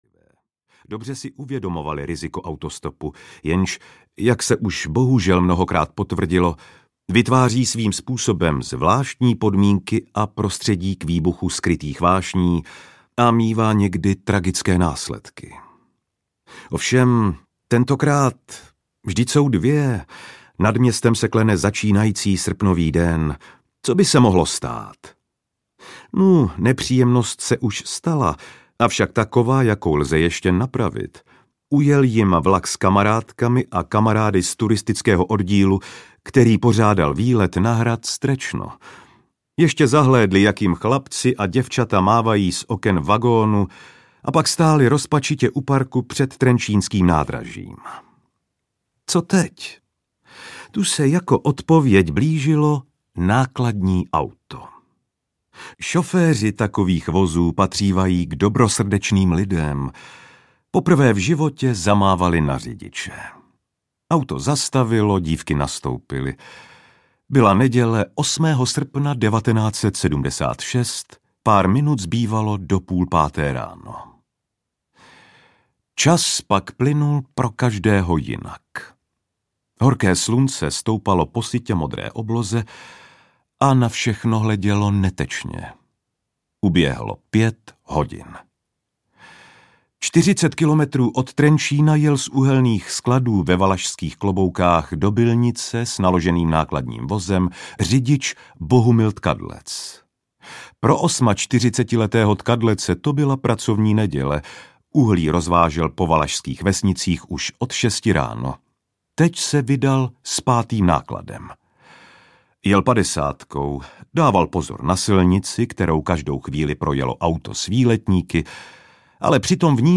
Skutečné kriminální příběhy audiokniha
Ukázka z knihy
Četby se ujal herec Petr Stach, představitel vyšetřovatele kapitána Anděla z televizního seriálu Případy 1. oddělení.
• InterpretPetr Stach